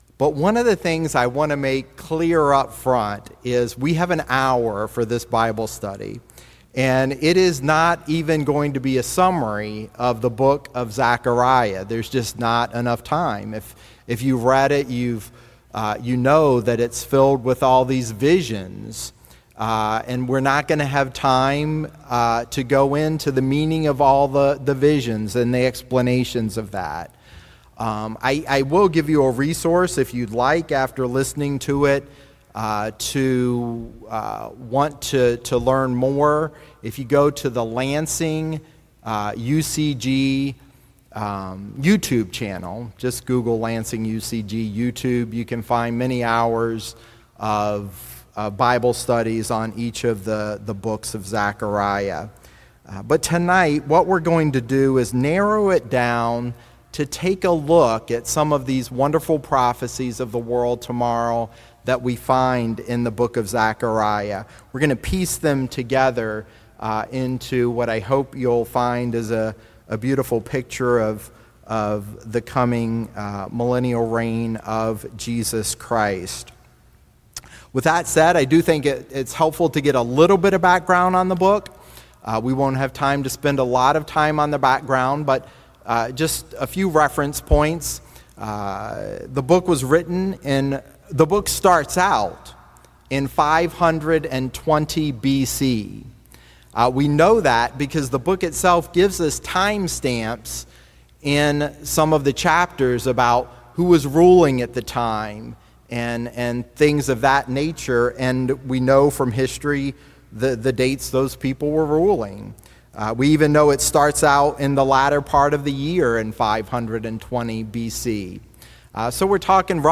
Given in Lake Geneva, Wisconsin